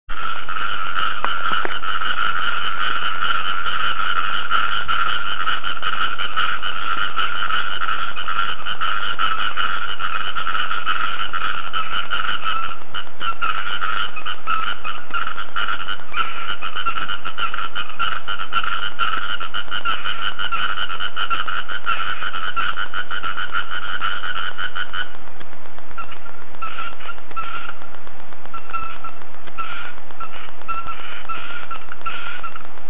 水たまりというと真っ先に蚊を思い浮かべるんですが、時期もあるんでしょうか予想がいい方に外れまして、夜になるとカエルたちが鳴き出しました。あまりにも間近なのでこちらの物音などですぐ鳴き止んでしまいますが、気長にチャンスをうかがって録音してみました。
後半の静かな部分にプチップチッとした音が入ってますが、うっかりしていて横の扇風機を回したまんまでした。
それと不思議なのは、後半部分で聞こえる鳥のもののような鳴き声です。
蛙さんにぎやかですねぇ、ウチもすぐ裏がたんぼなんで梅雨時になると蛙の合唱が始まります。
つまり録音状態にして窓際に置くだけ。